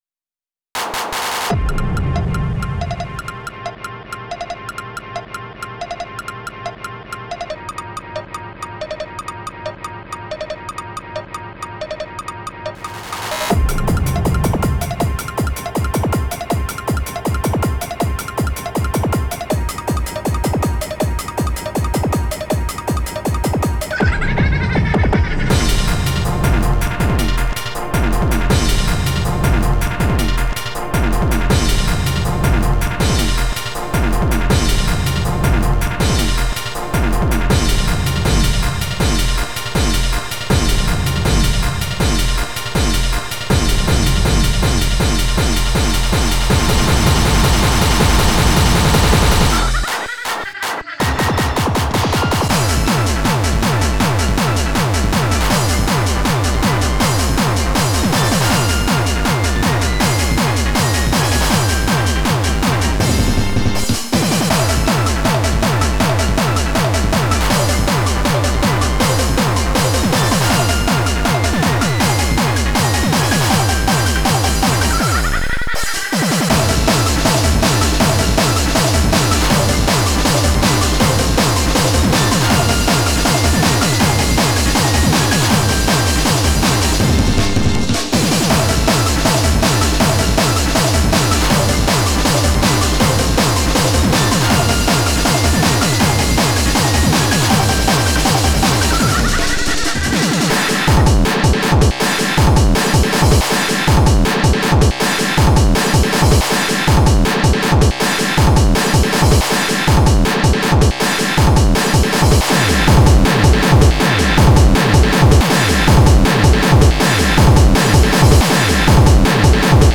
この曲はエネルギーに満ち溢れていて、レイヴで聞かれそうな曲です
ありがたいことに、修正できたので、音は良くなりました…